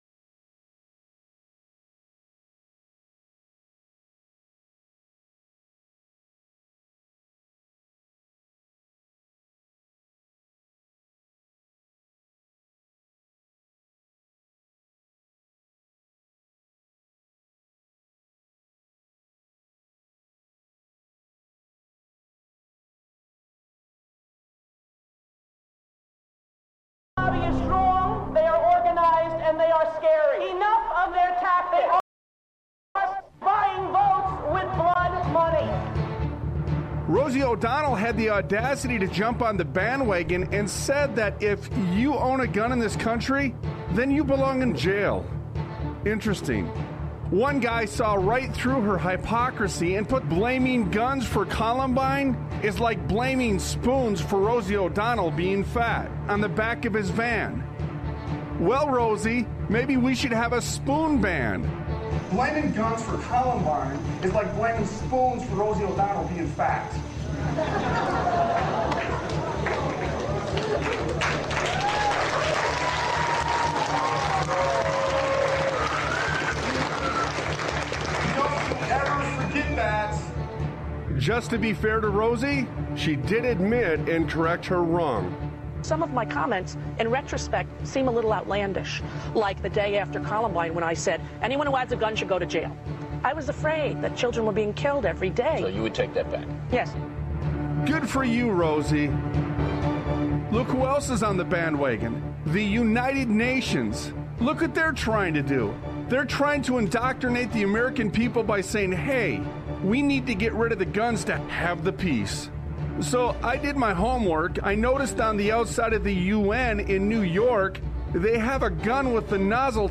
Talk Show Episode, Audio Podcast, Sons of Liberty Radio and The Chaos They Are Creating: Is The Resolve Found In Man Or God? on , show guests , about The Chaos They Are Creating,Is The Resolve Found In Man Or God, categorized as Education,History,Military,News,Politics & Government,Religion,Christianity,Society and Culture,Theory & Conspiracy